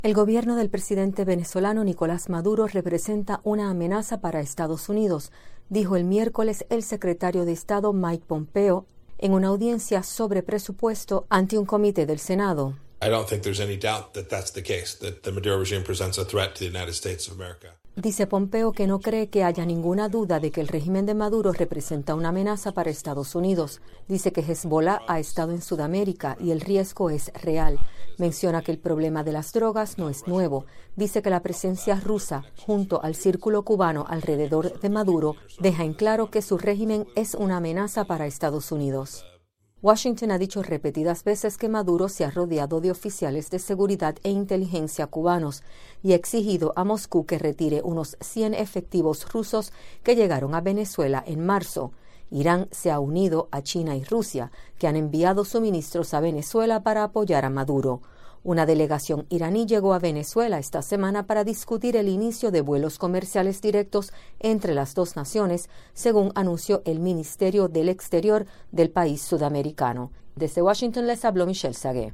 El secretario de Estado Mike Pompeo testifica en una audiencia ante el Comité de Relaciones Exteriores del Senado.